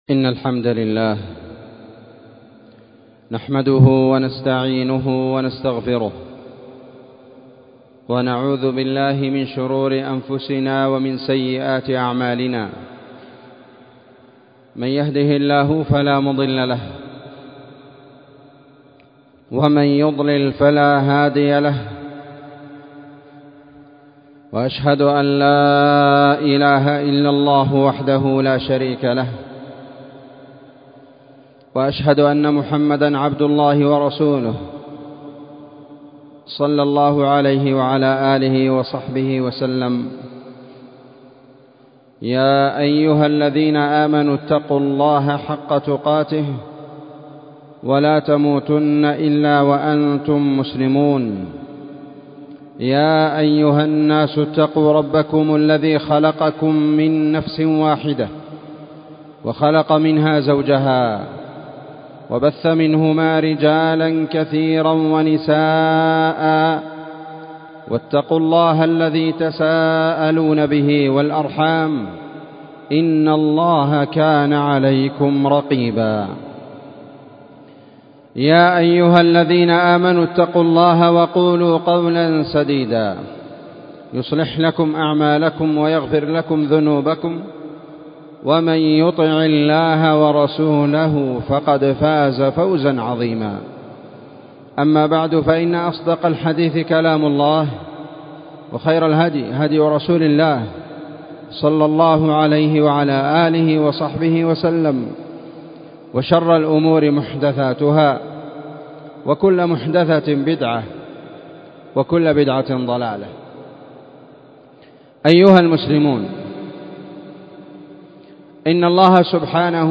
خطبة قيمة